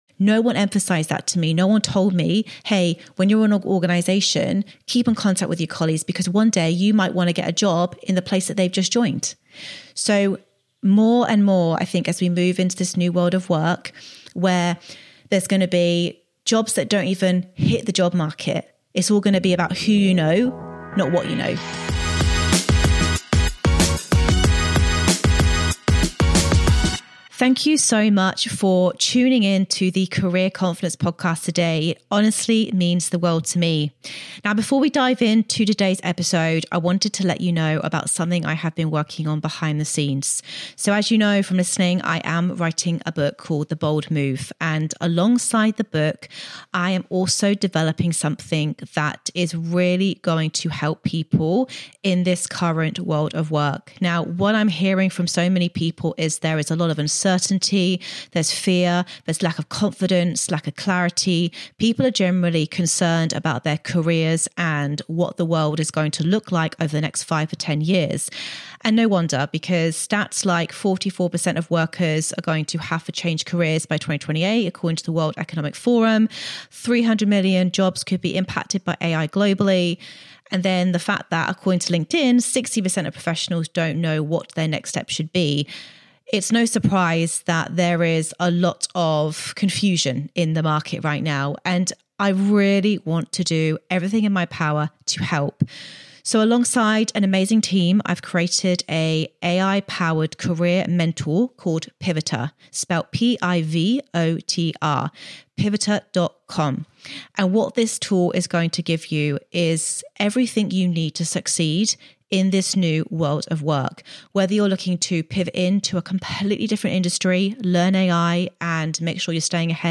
Expect real stories, candid laughs, and practical takeaways you can act on before the week is out.